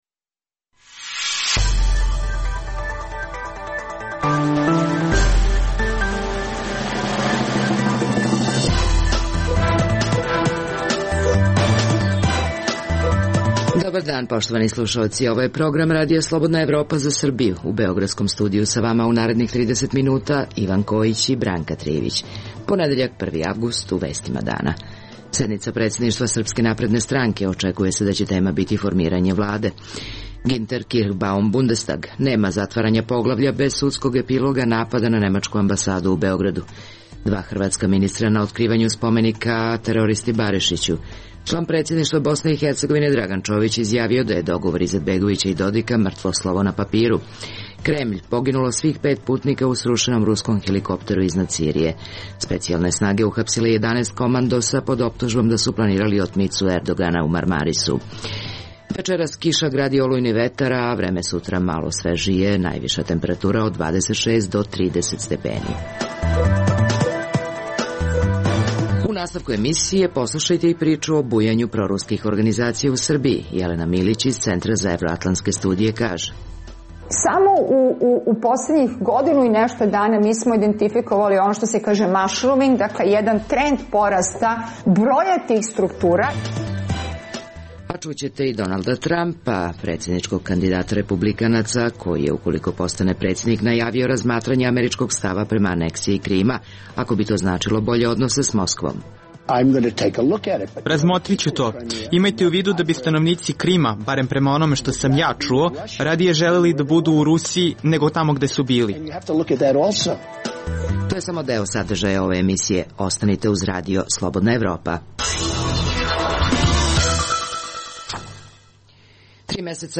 Emisija namenjena slušaocima u Srbiji. Sadrži lokalne, regionalne i vesti iz sveta, tematske priloge o aktuelnim dešavanjima iz oblasti politike, ekonomije i slično, te priče iz svakodnevnog života ljudi, kao i priloge iz sveta.